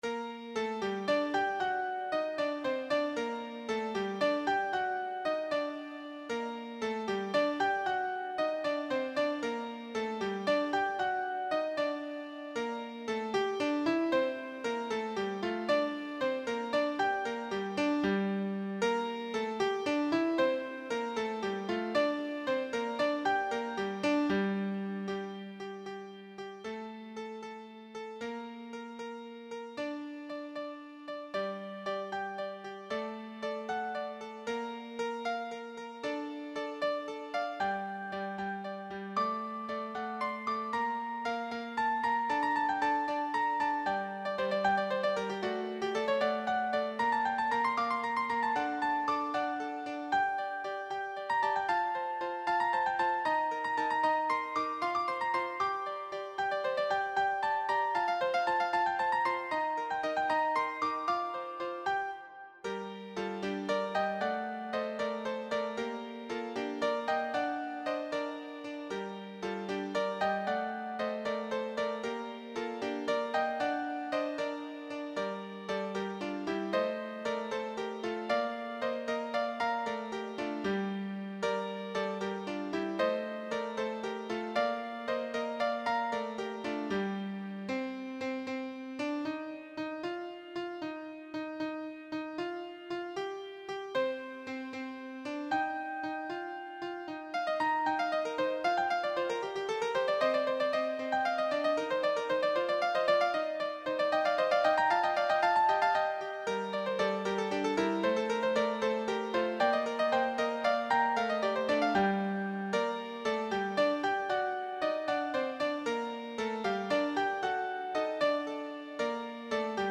Arrangiert als 3-er Bourrée, Schnellwalzer
Tonumfang G-D3, G-Moll
midi Tonbeispiel